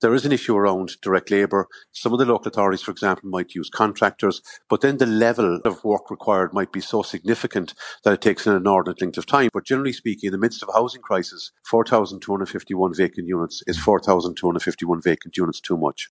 Michael McCarthy, Chair of the NOAC, says vacant homes are contributing to housing shortages…………..